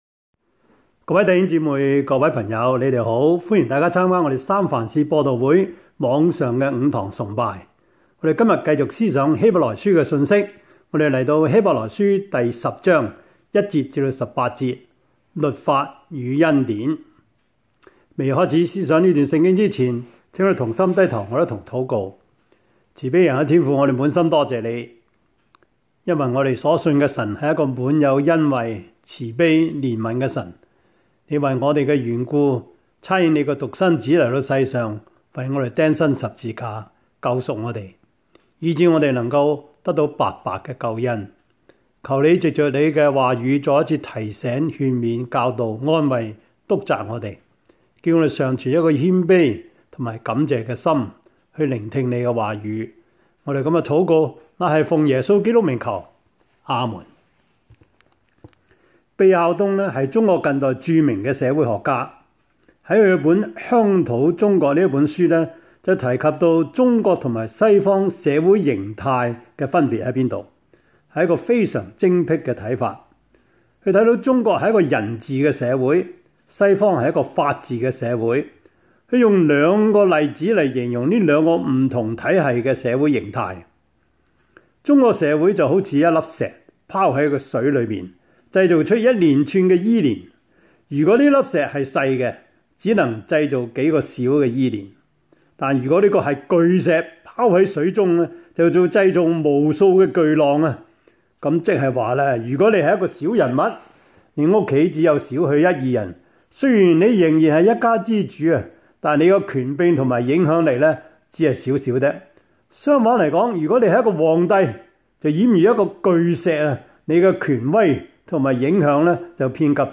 Service Type: 主日崇拜
Topics: 主日證道 « 耶穌基督的福音 第十七課: 義和團與殉道血 »